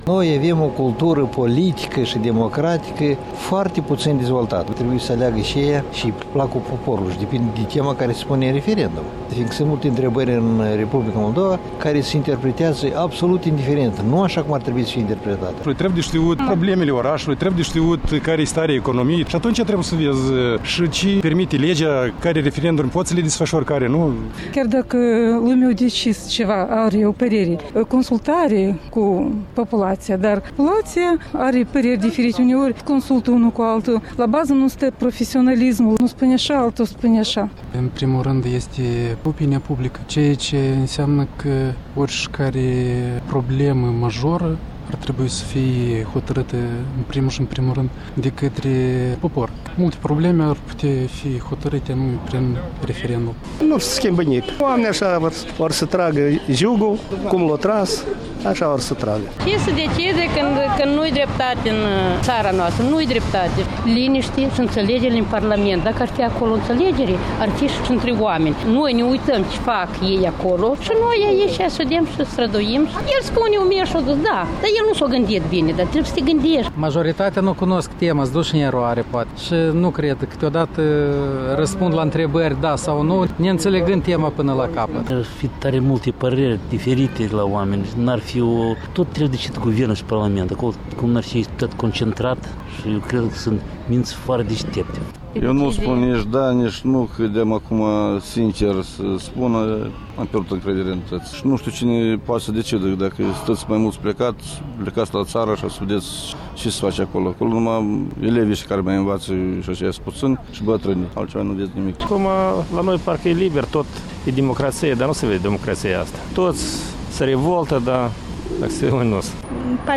Vox populi: la ce este bun un referendum?